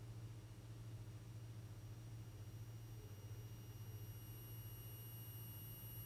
Now I’m noticing a noise from the bms.
With my iphone I registered a track of ~38dBA, I don’t know if it’s useful :
That is an inductor whine from the buck-regulator and it’s normal for this application with the used components.
The inductor’s magnet wire is slightly vibrating with the ON-OFF pulses (EMF causes a physical force to be enacted on the wire) and it has just enough movement that it transmits into the surrounding air, which in this case is a high pitch whine.
I asked because of the pitch that seemed to be pretty loud.